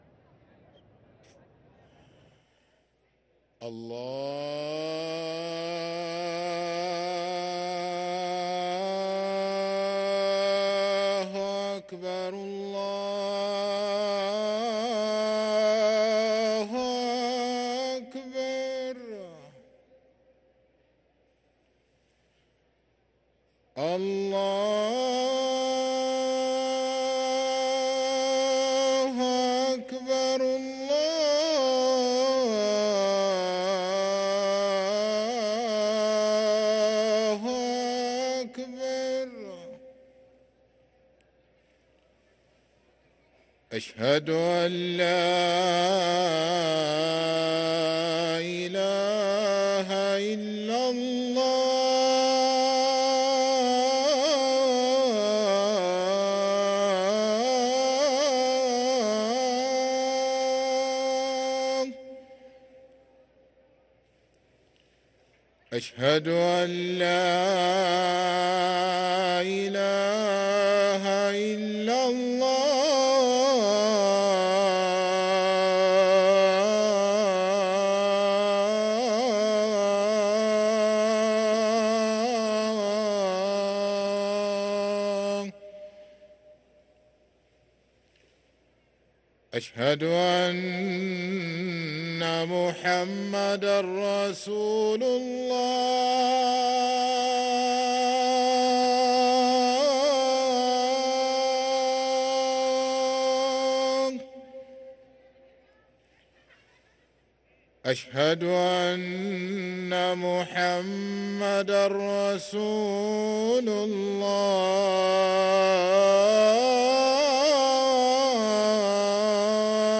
أذان العصر للمؤذن سعيد فلاته الاثنين 21 شعبان 1444هـ > ١٤٤٤ 🕋 > ركن الأذان 🕋 > المزيد - تلاوات الحرمين